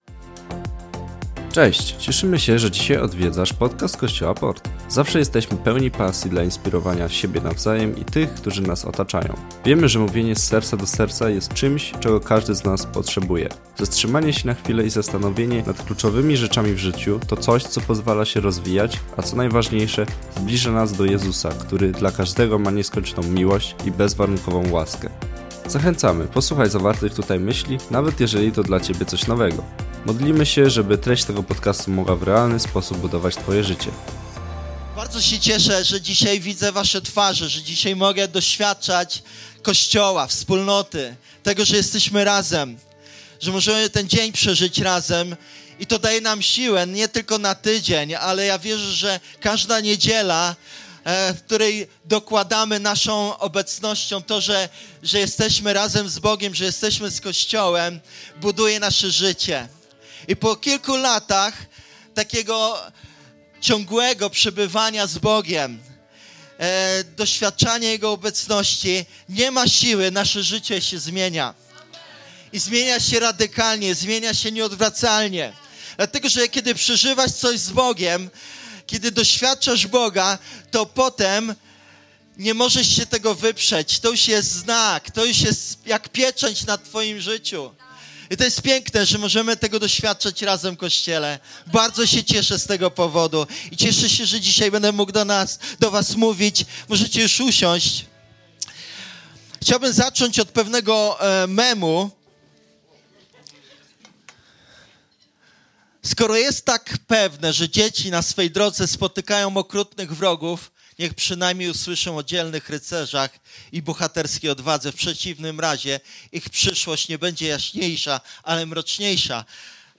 Co powoduje, że możemy czuć lęk, a jednak postąpić odważnie? To drugie kazanie z naszej tegorocznej serii o tym, co może się wydarzyć, kiedy zaufamy Bogu i posłuchamy Jego głosu.